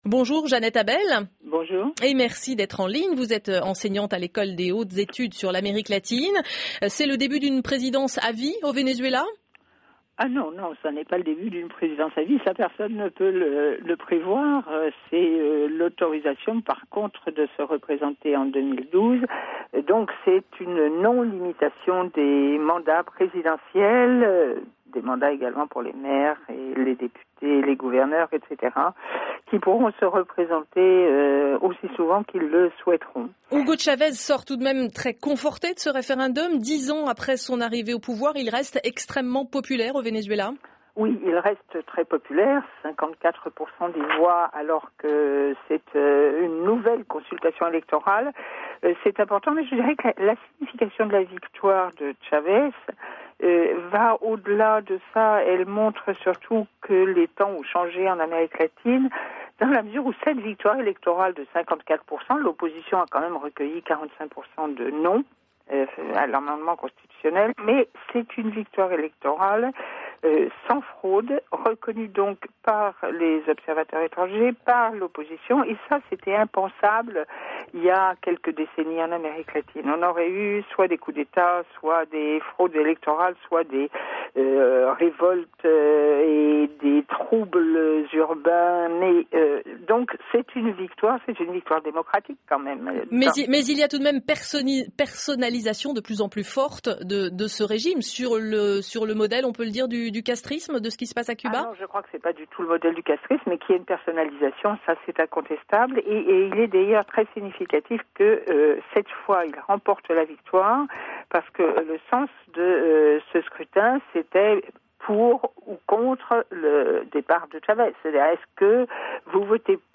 Entretien diffusé sur Radio France Internationale, le 16 février 2009.